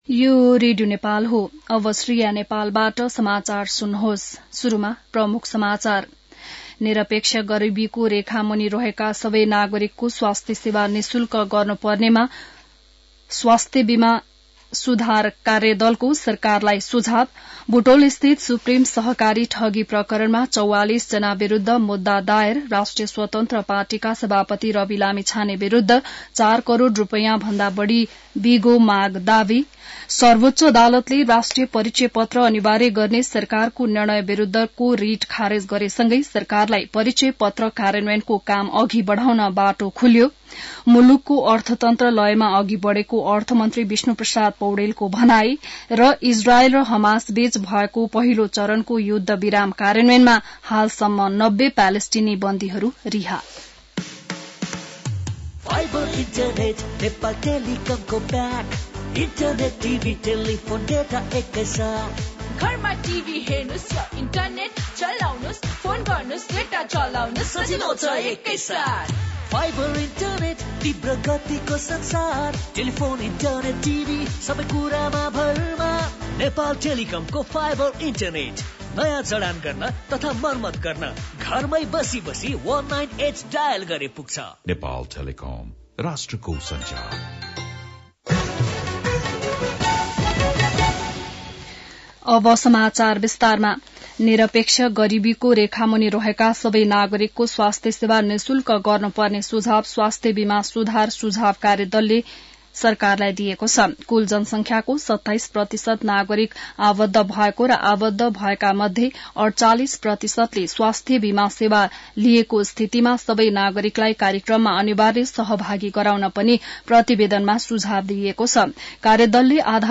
बिहान ७ बजेको नेपाली समाचार : ८ माघ , २०८१